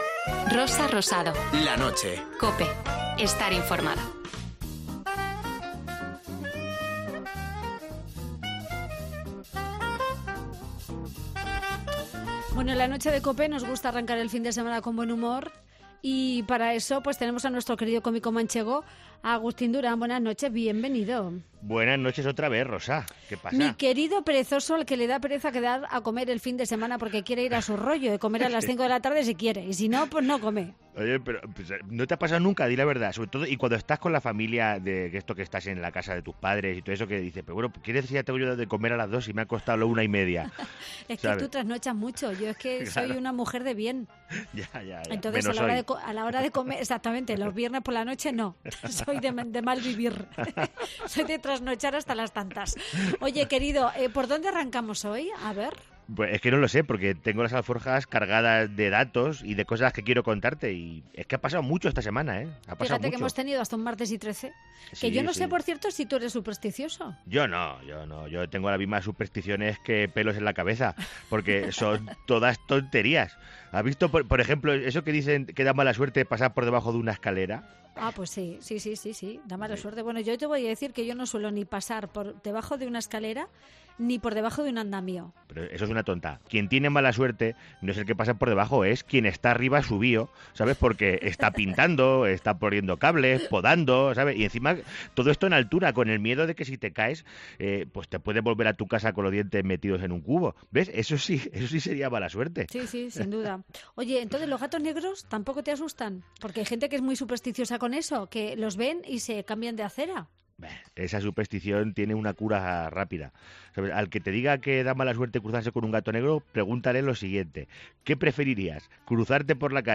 Este sábado hablamos con él de supersticiones, hacemos una parada en la prensa vasca y le dedicamos una canción al moño de Pablo Iglesias.